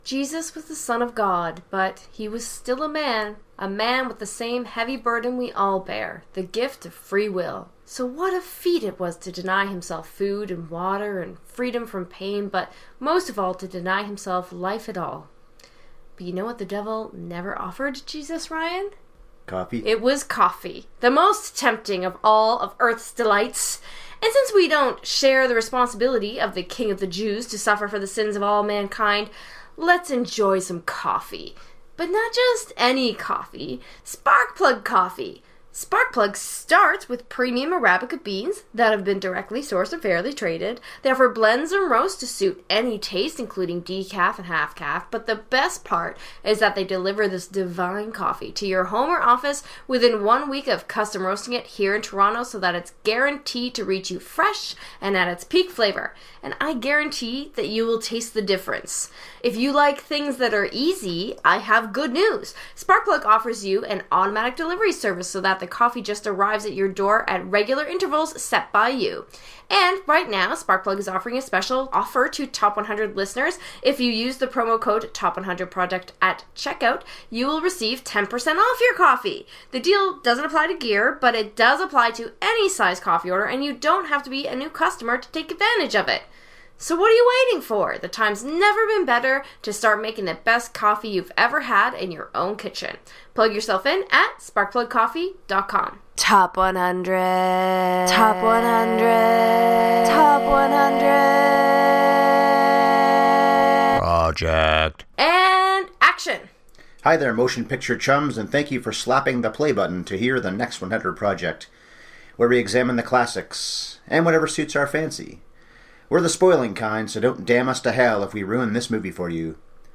The Next 100 Project reaches episode #100 and we do it with an in-depth pre-Easter chat about a life-changing Jesus movie. Willem Dafoe is galvanizing as the Big Guy in Marty Scorsese’s passion project, but what stands out the most are the revolutionary ideas in the story and in the script.